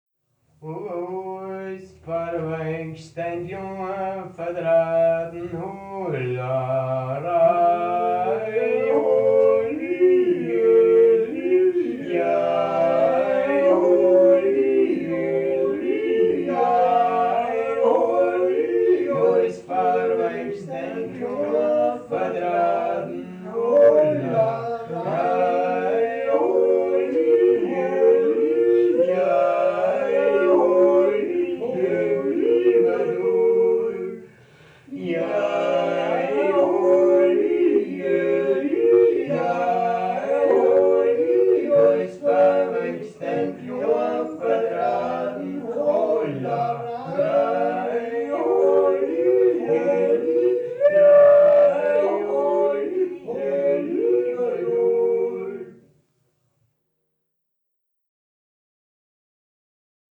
Jodler und Jodler-Lied – Ungeradtaktig
Yodel, Yodel-Song – triple metre (3/4): Lower Austrian and Styrian Wechsel-region; social structure; local dialect
Folk & traditional music